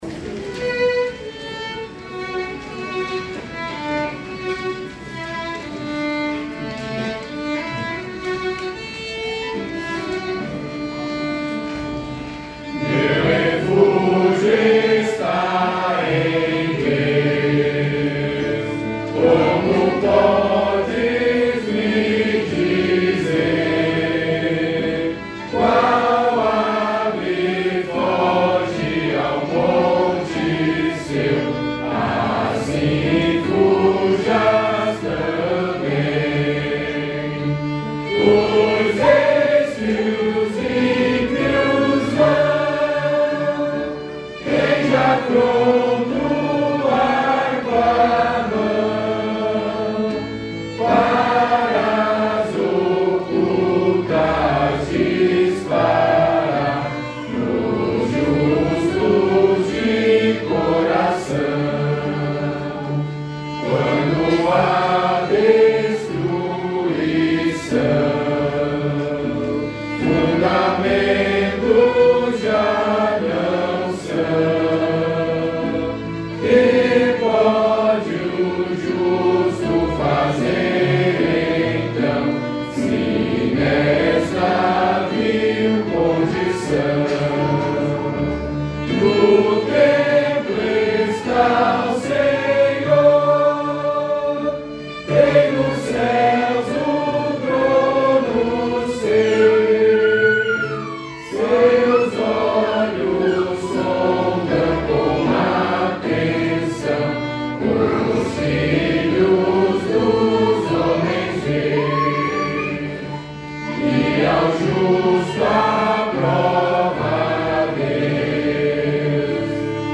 salmo_11B_cantado.mp3